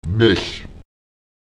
Lautsprecher mex [mE§] sie, ihnen (3. Person Plural bei niederen Lebensformen)